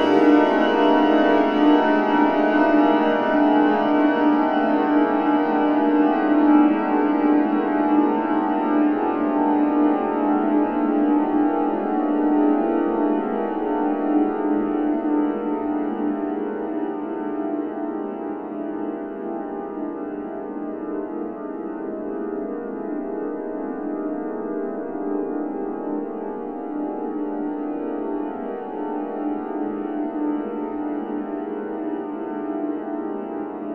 PIANO SWIP.wav